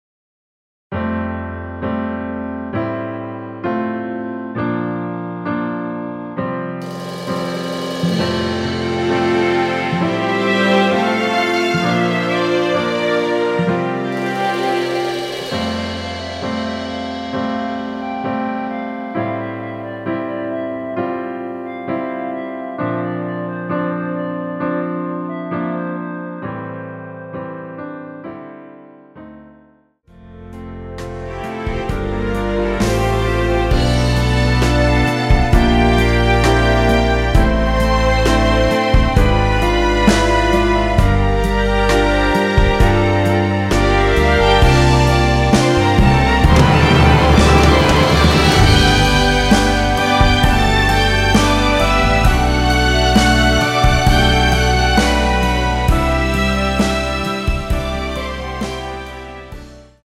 원키 멜로디 포함된 MR 입니다.(미리듣기 참조)
노래방에서 노래를 부르실때 노래 부분에 가이드 멜로디가 따라 나와서
앞부분30초, 뒷부분30초씩 편집해서 올려 드리고 있습니다.
중간에 음이 끈어지고 다시 나오는 이유는